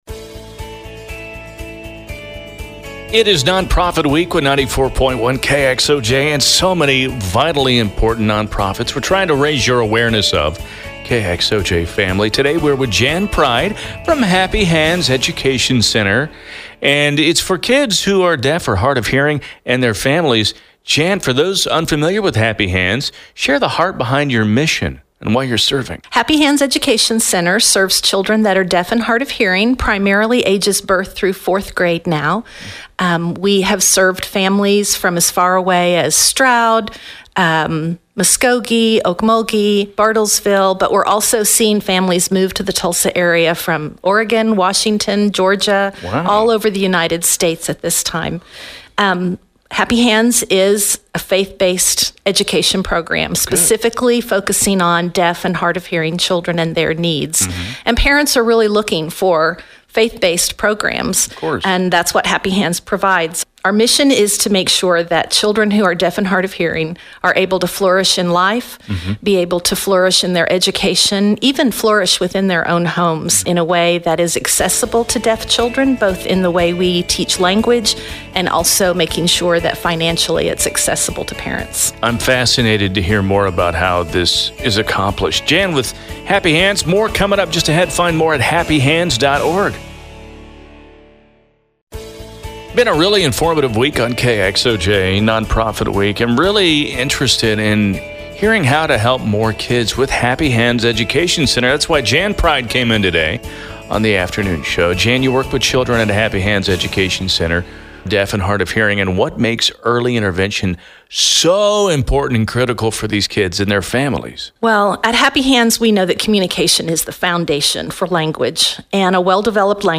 Interview Featured on KXOJ During Nonprofit Week Click above to listen to all 8 of the interview slots our Executive Director